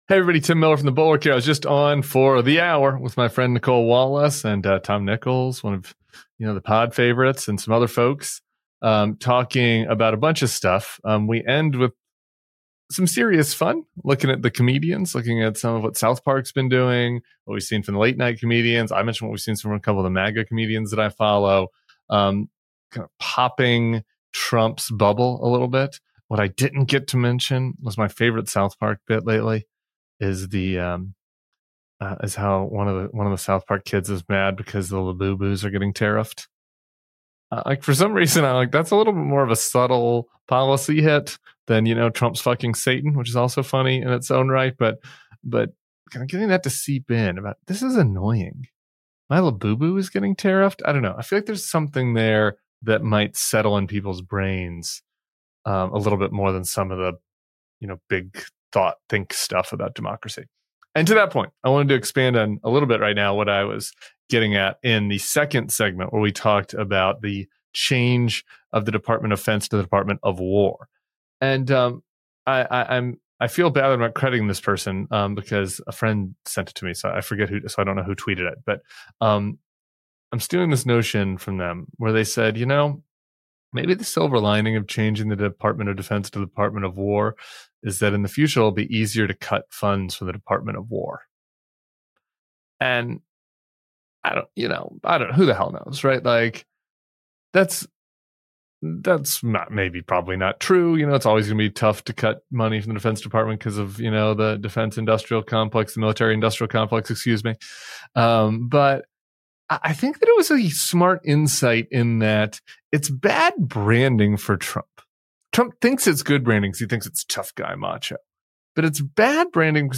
Tim Miller joins Nicolle Wallace on MSNBC’s Deadline: White House to take on Trump’s ‘Department of War’ rebrand, the collapse of his anti-war image, and how comedians from South Park to MAGA comics are skewering his tough-guy act.